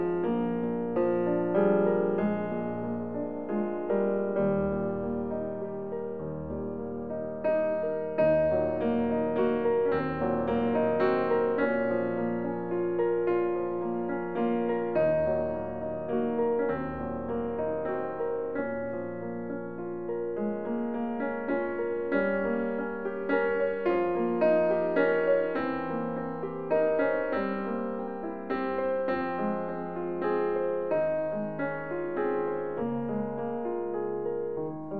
Жанр: Классика
Classical